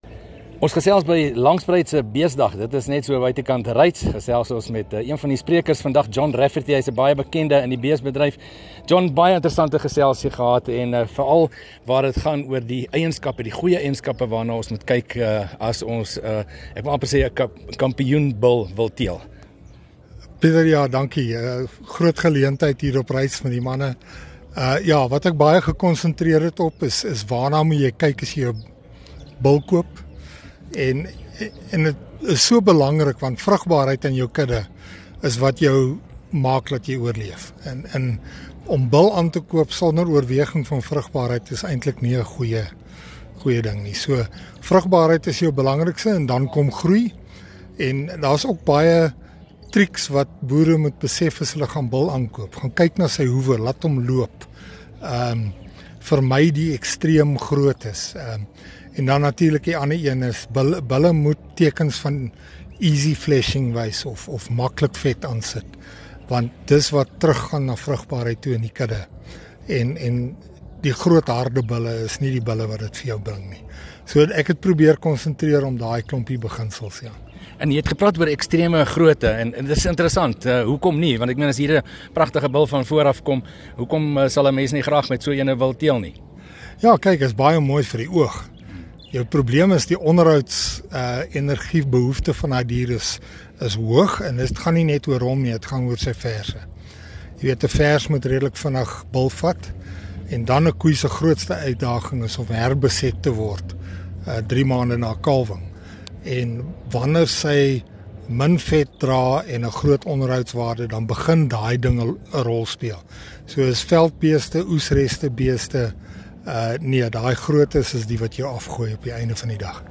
Landbou Insetsels 24 Mar Teelwaardes van bulle 2 MIN Download